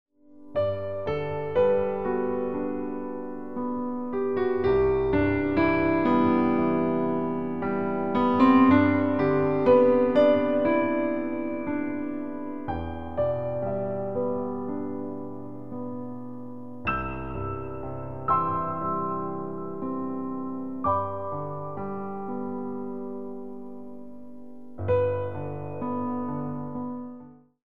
A Modern Dance Choreography CD
18 Instrumental Compositions  /  various orchestrated moods.